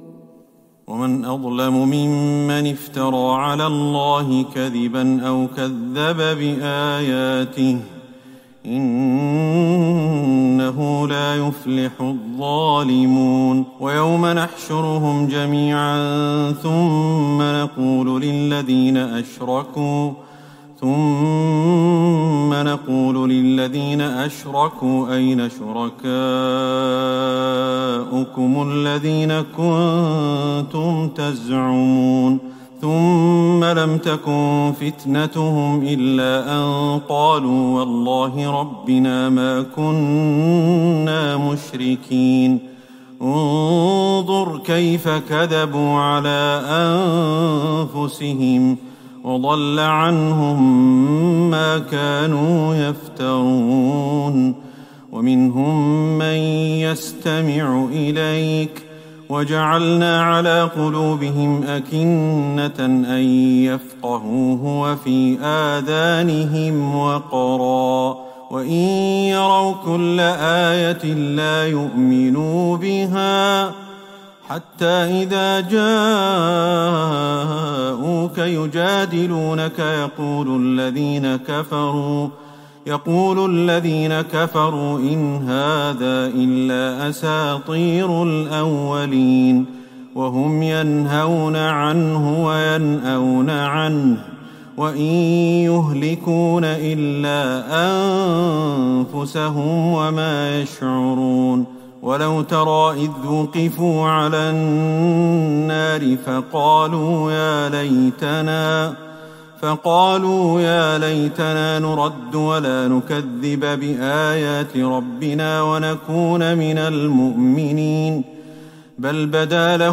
ليلة ٩ رمضان ١٤٤١هـ من سورة الأنعام { ٢١-٧٣ } > تراويح الحرم النبوي عام 1441 🕌 > التراويح - تلاوات الحرمين